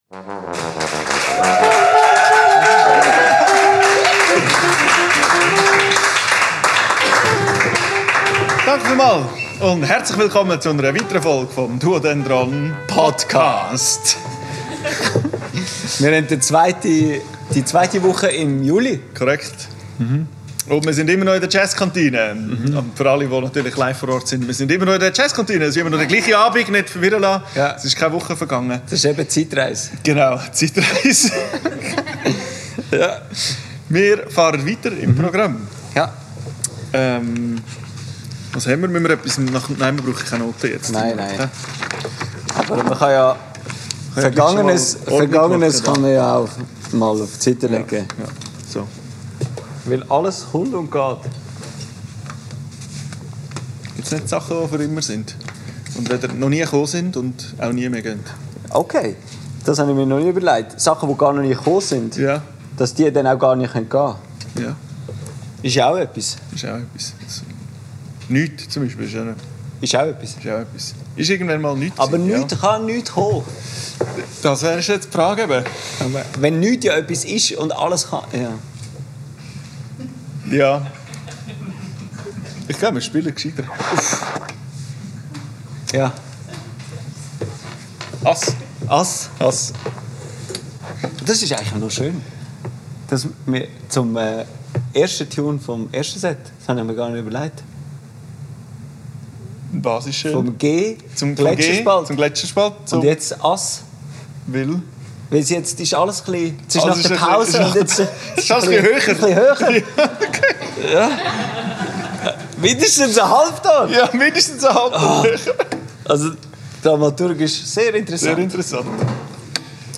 Teil 2 des Live-Podcasts aus der Jazzkantine Luzern.
Aufgenommen am 26.06.25 in der Jazzkantine Luzern.